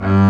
Index of /90_sSampleCDs/Club-50 - Foundations Roland/STR_xStr Quartet/STR_xCello&Bass